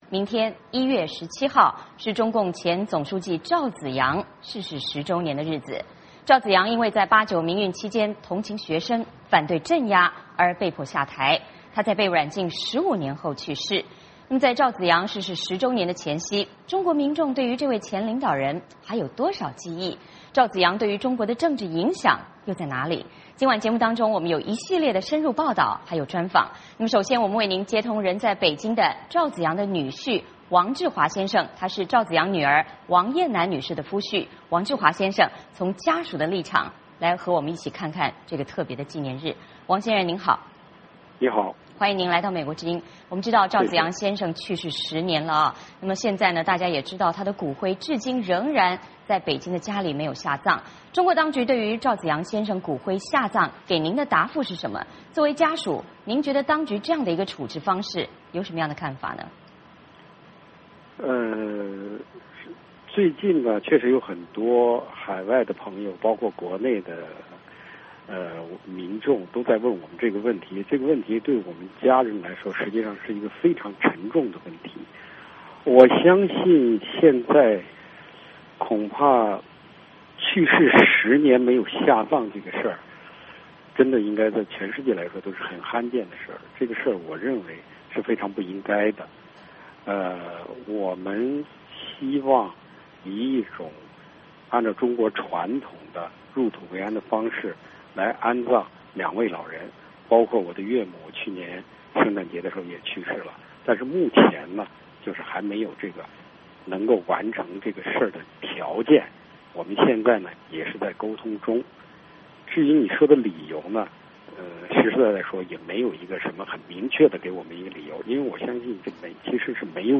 在赵紫阳逝世十周年的前夕，中国民众对这位前领导人还有多少记忆?赵紫阳对中国政治的影响在哪里?今晚节目中我们有一系列深入报道与专访。